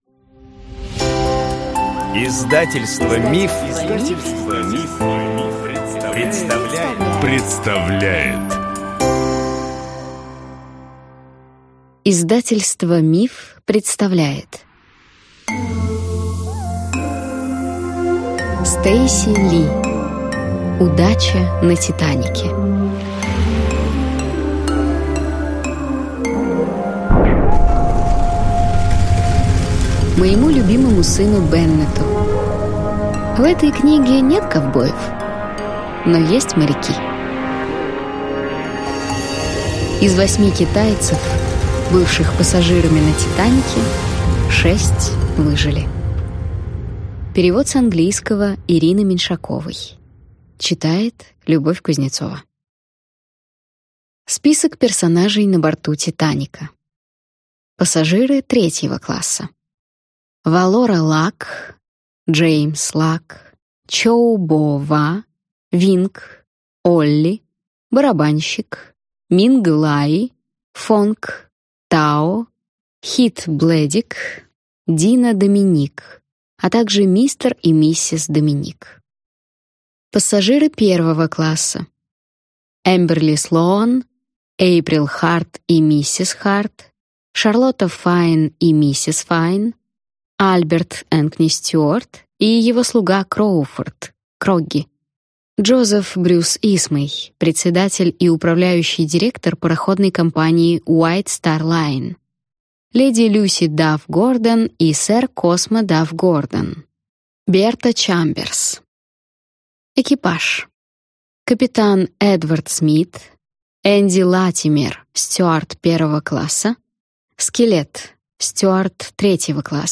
Аудиокнига Удача на «Титанике» | Библиотека аудиокниг